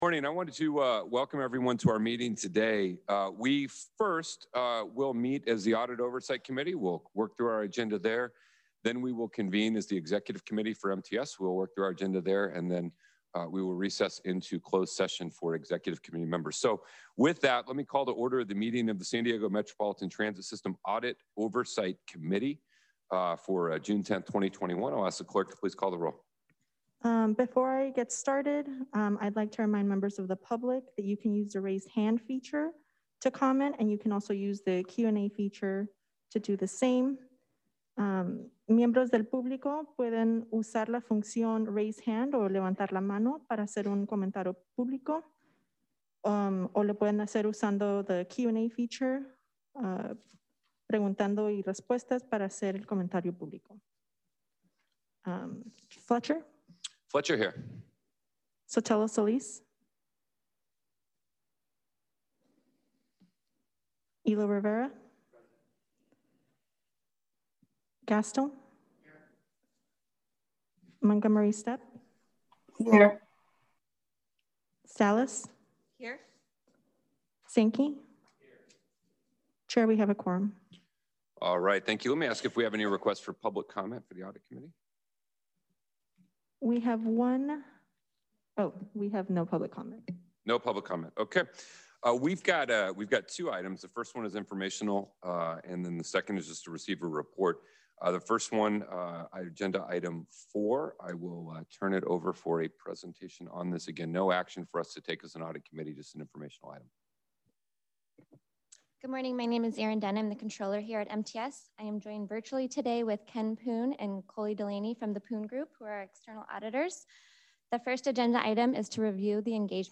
AUDIT OVERSIGHT COMMITTEE (AOC) MEETING